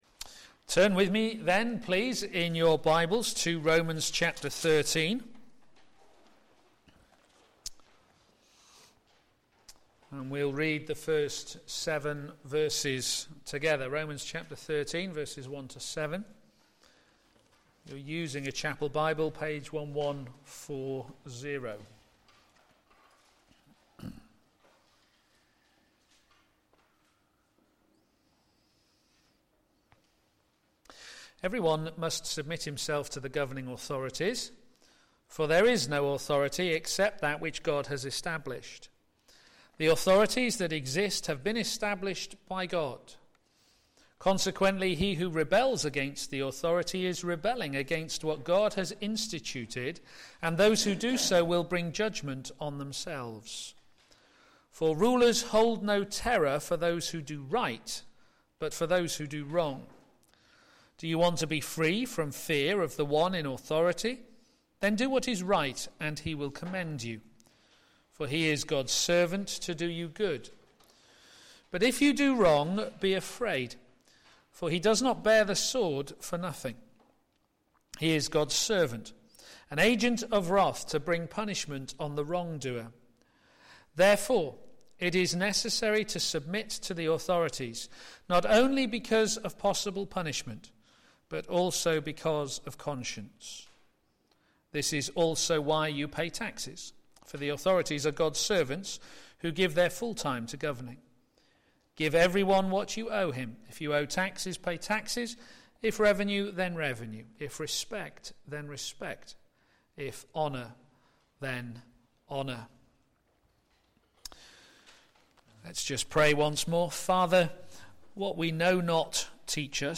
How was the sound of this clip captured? p.m. Service